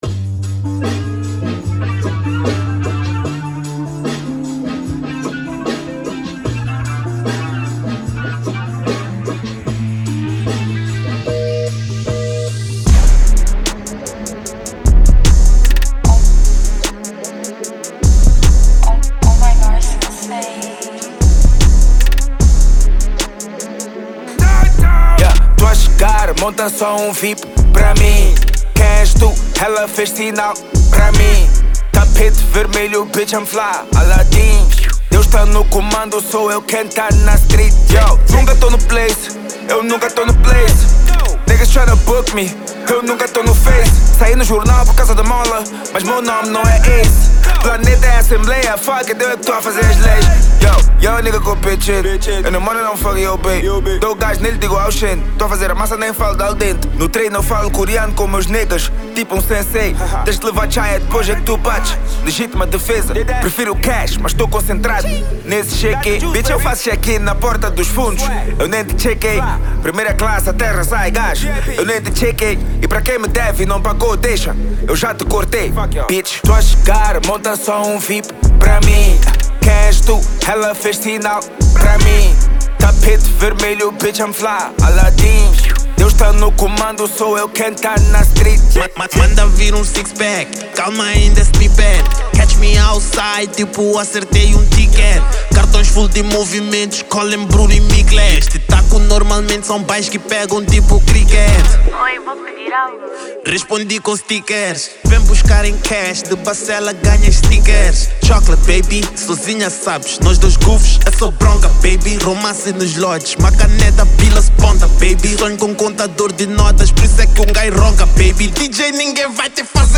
Genero: Trap